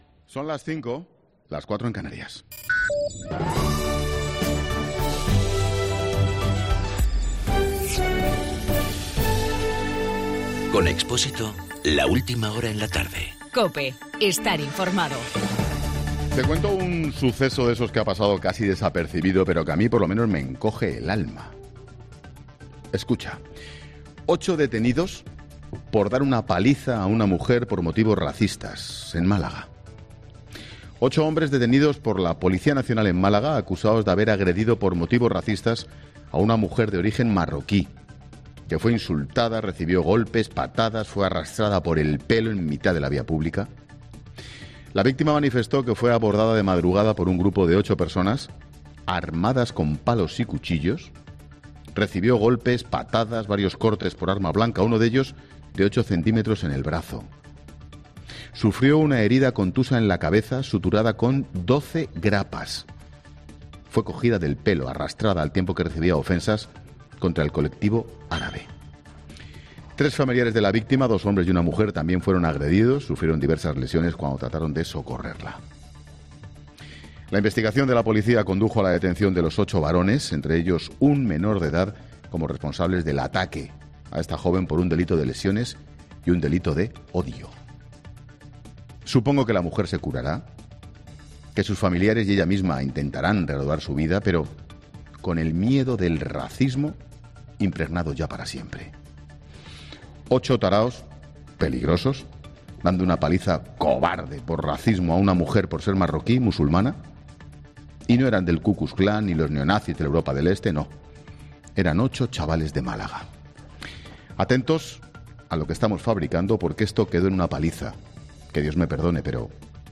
AUDIO: Monologo 17 h.